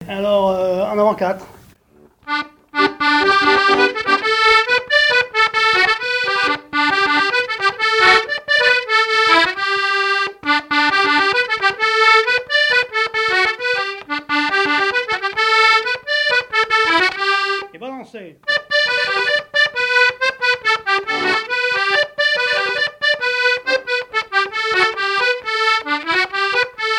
Quadrille - En avant quatre
danse : avant-quatre
Pièce musicale inédite